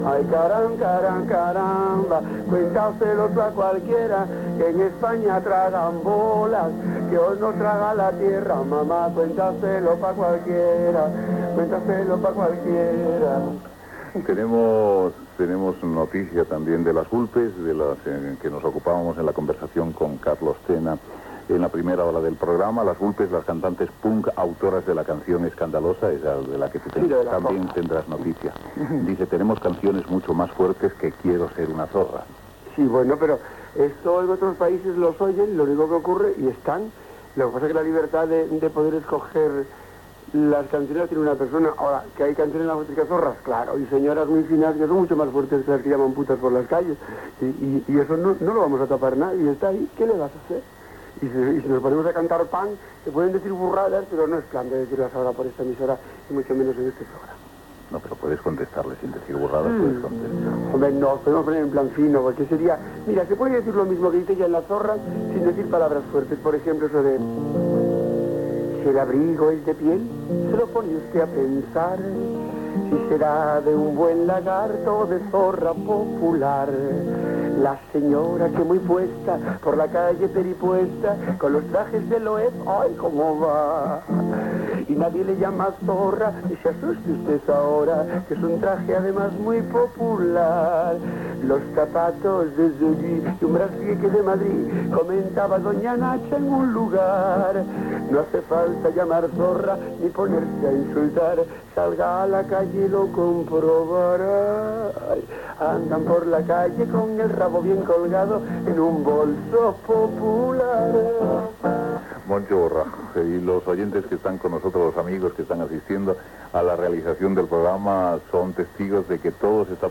Improvisacions cantades i recitades de l'humorista Moncho Borrajo sobre el grup Las Vulpes, els gallegs i Valladolid.
Info-entreteniment